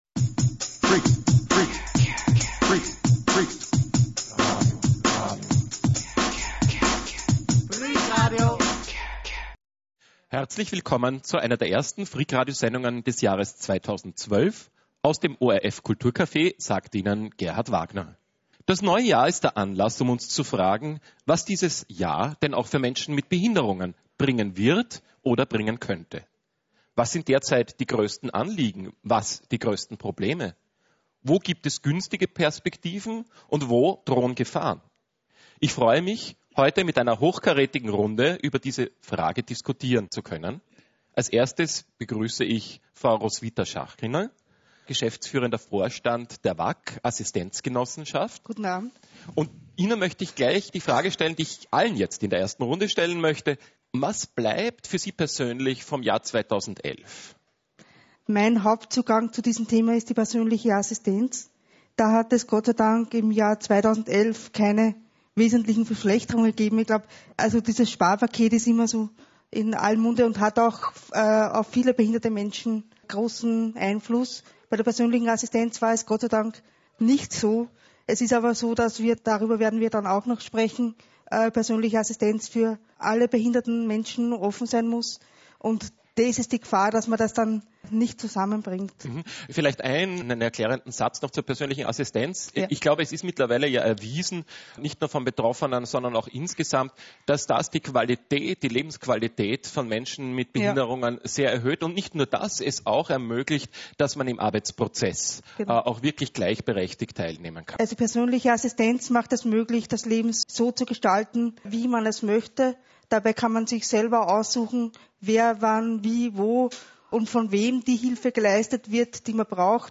Vertreter in eigener Sache diskutieren mit einem Ex-Behindertensprecher und Sachwalter im KulturCafe.
Die Musik der Sendung stammt von Claudio Monteverdi Worüber in dieser Sendung diskutiert wird: Was bleibt vom Jahr 2011?